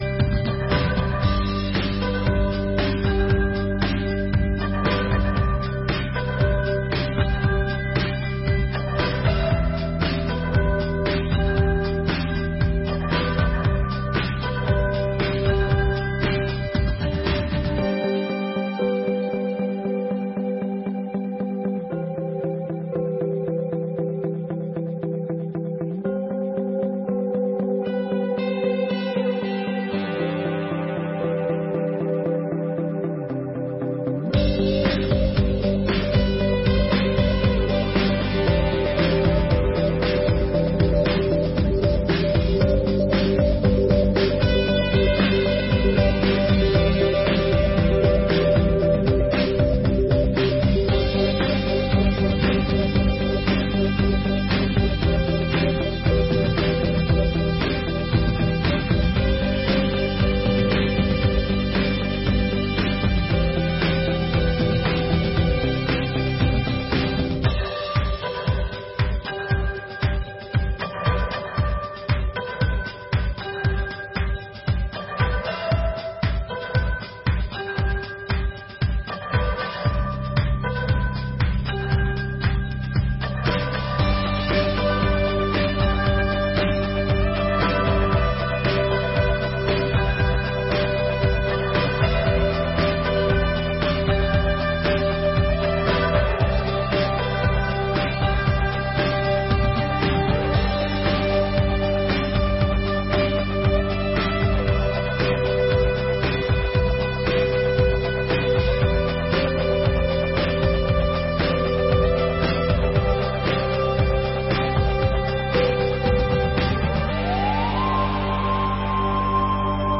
Audiências Públicas de 2023